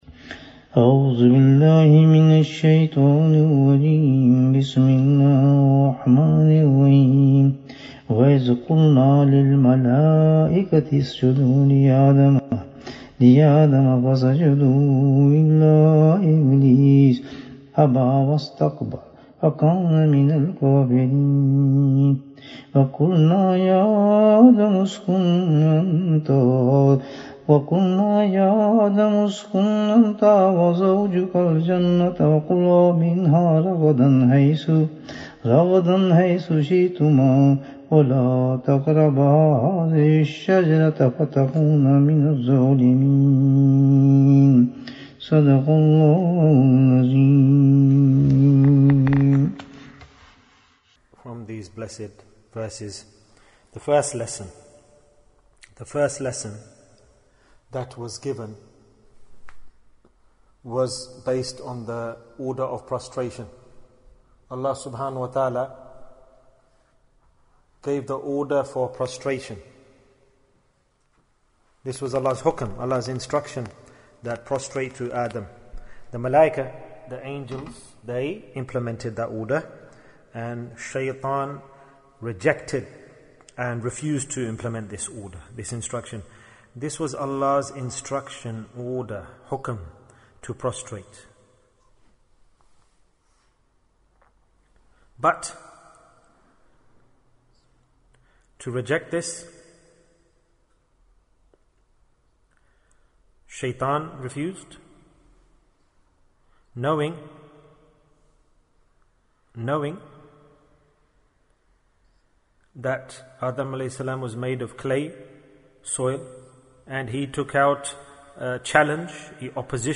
Two Messages for Us - Dars 37 Bayan, 43 minutes20th July, 2020